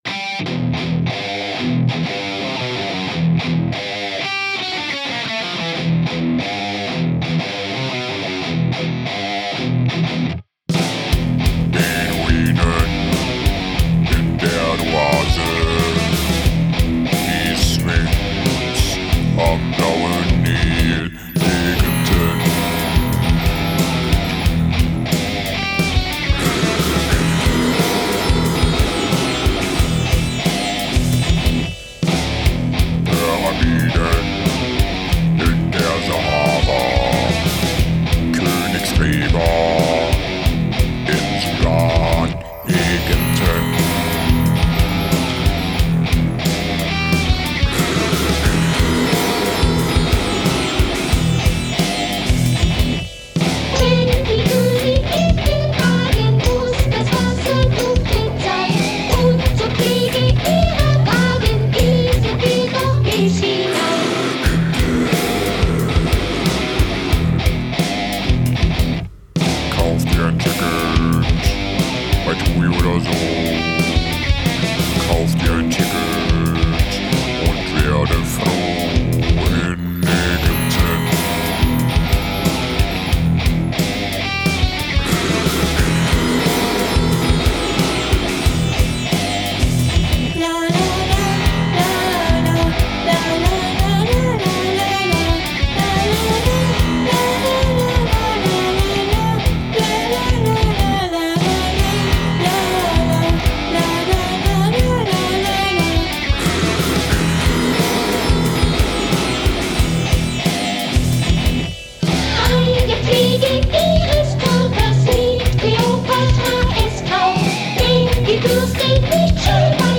Metal-Mix (Genre unbekannt) verbessern: Ägypten
Hmm also wenn ich alle Compressoren erstmal runterschmeisse und Ozone ausmache, hört es sich so an: Die Instrumente klingen flach, da sind doch überall Comps drauf, oder muss ich ins Bett?